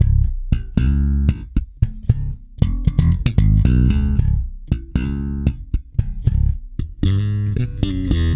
-JP THUMB.C#.wav